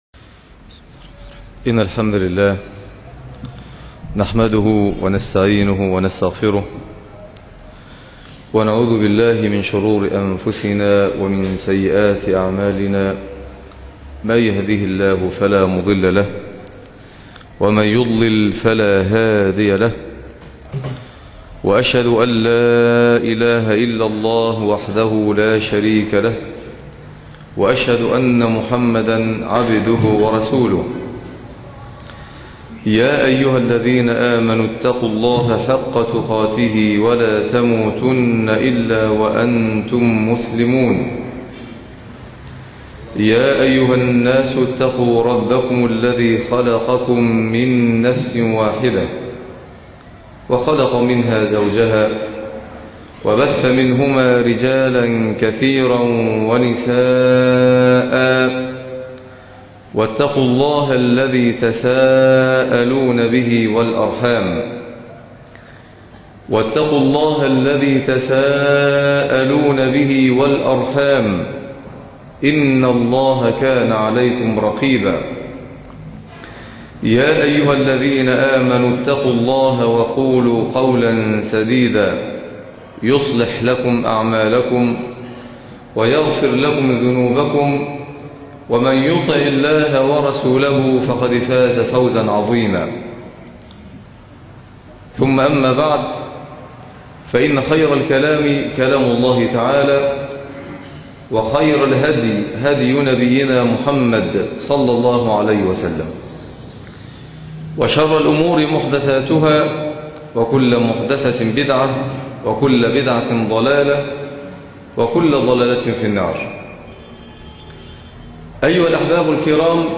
ثوابت على طريق الدعوة - خطب الجمعة